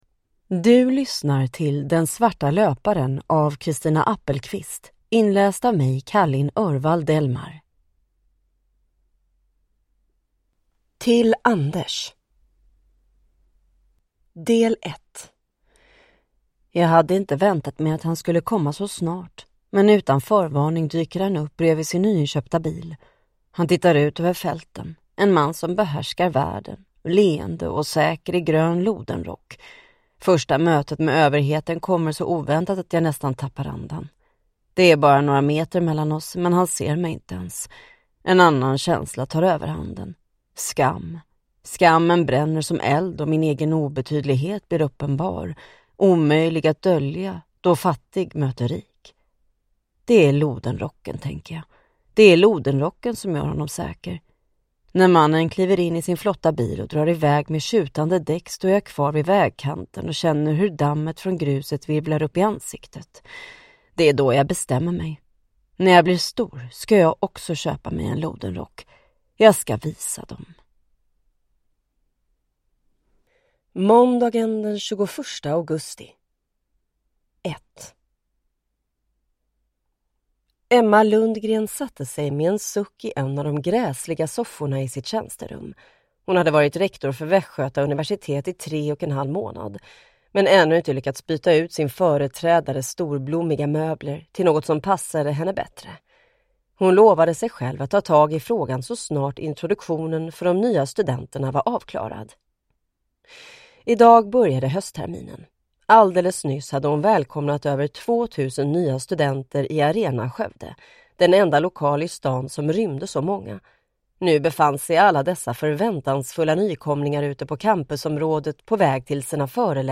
Ljudbok
Nyinspelad ljudbok med förbättrat ljud och populär inläsare!